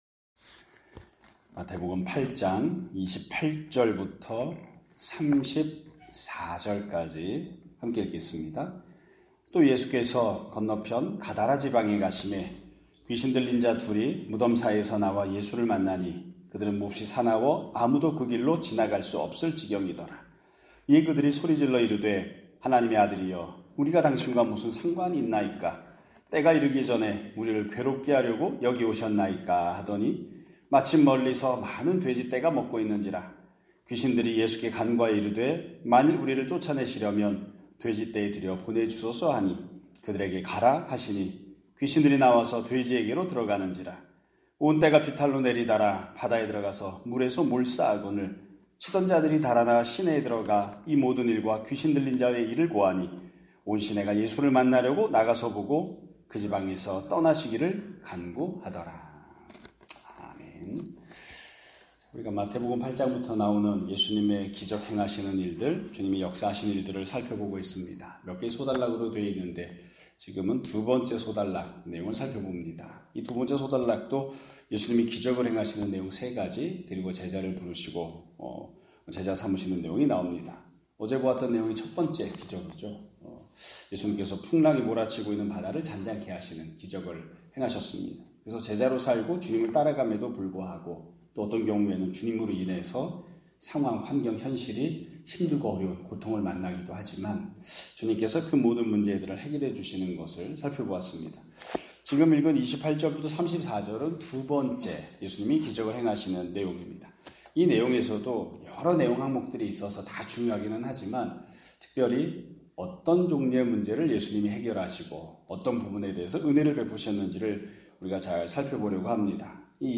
2025년 7월 17일(목요일) <아침예배> 설교입니다.